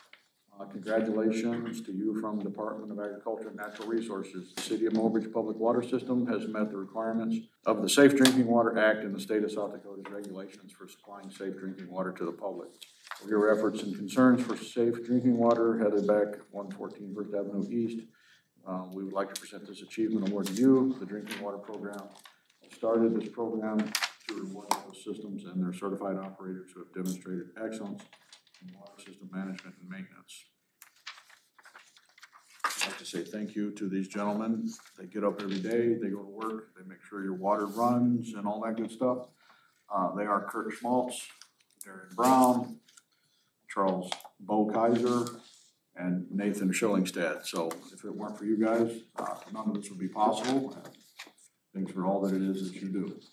The City of Mobridge and its Water and Sewer Department employees were recognized at Wednesday night’s City Council meeting with awards for providing safe drinking water to the public.  Mobridge Mayor Gene Cox read the press release from the Department of Agriculture and Natural Resources.